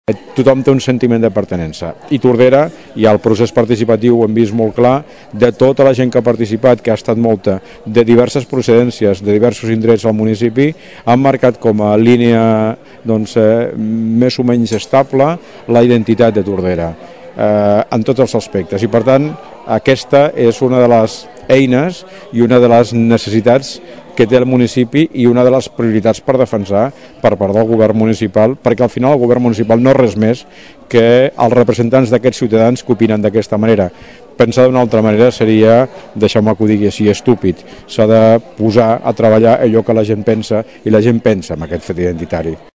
acte ciuJoan Carles Garcia, alcalde de Tordera i candidat a la reelecció per a les properes municipals, va presentar ahir el projecte de futur per al municipi en un acte públic al Teatre Clavé.